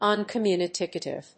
/`ʌnkəmjúːnəkèɪṭɪv(米国英語)/